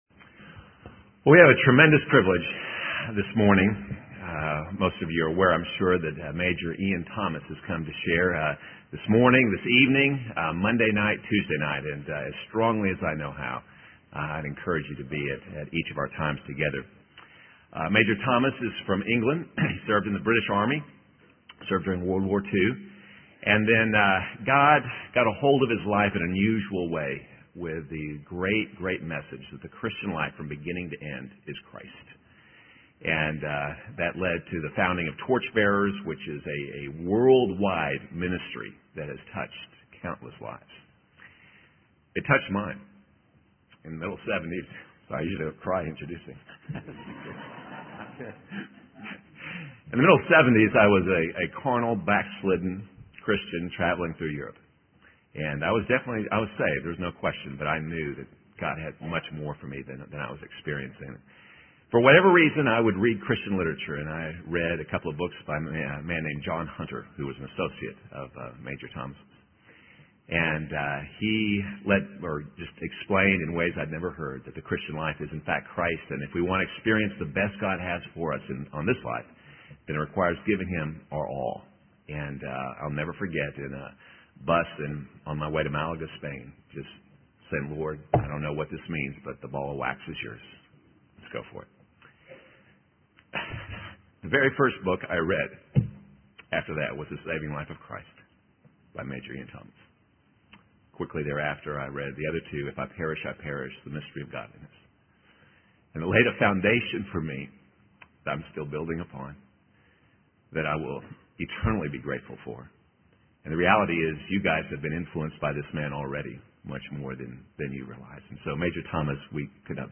In this sermon, the speaker emphasizes the importance of understanding the resurrection of Jesus in the life of every believer. He refers to the Gospel of Luke and the story of the women who came to the apostles to announce that Jesus is alive.